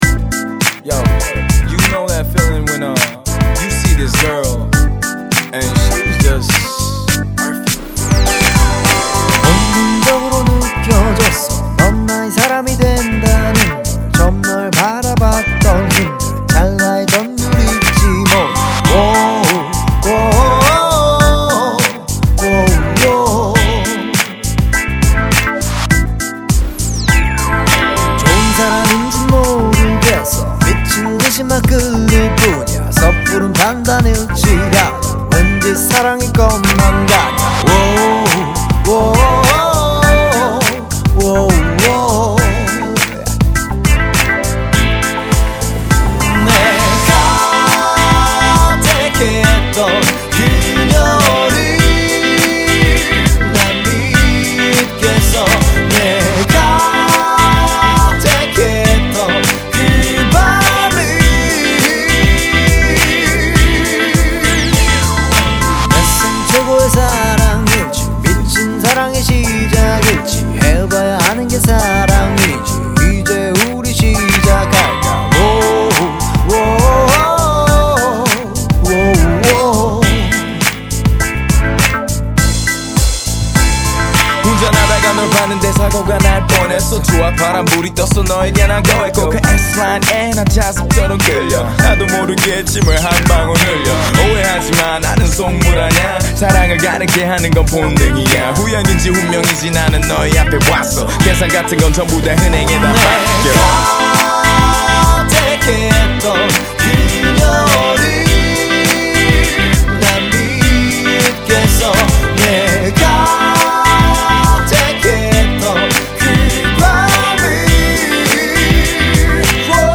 아카펠라 뽑아서 해봤습니다.
훅에서 코드가 맞는듯 안맞는듯 하네요.
코드가 안맞는 다고 느껴진 부분은 없었습니다!